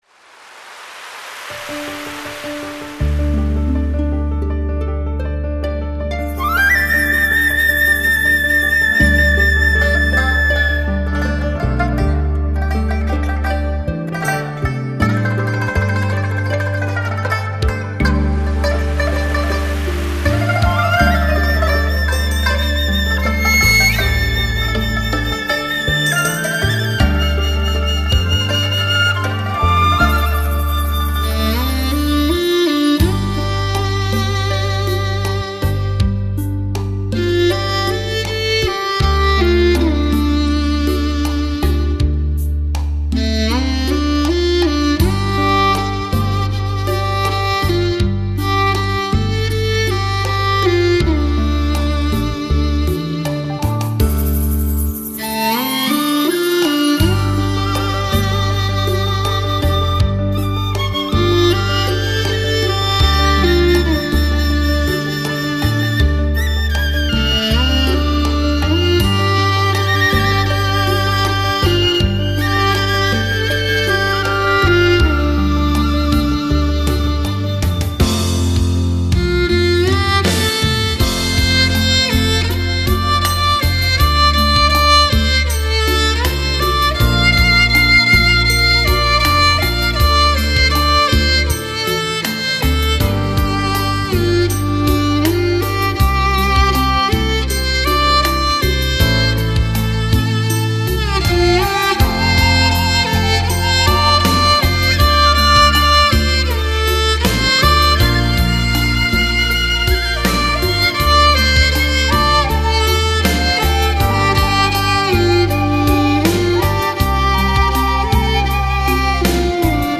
马头琴联袂新疆各民族乐器
与热瓦普、弹拨尔、艾捷克和谐融合